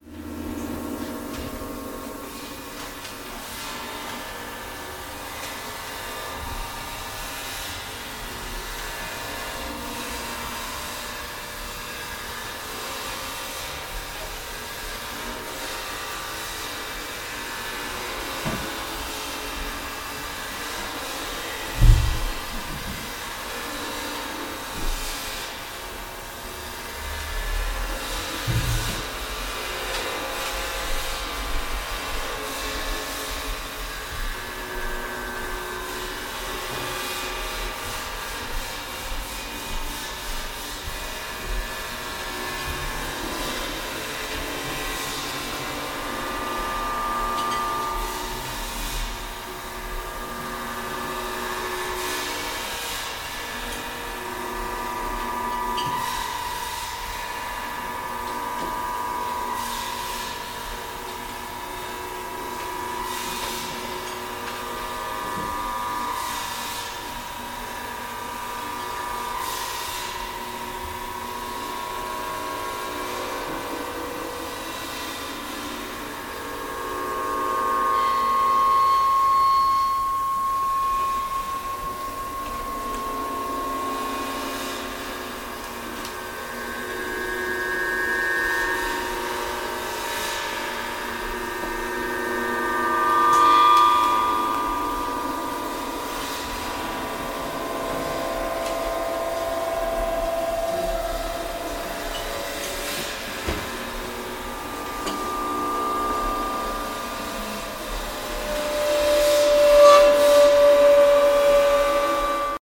French pianist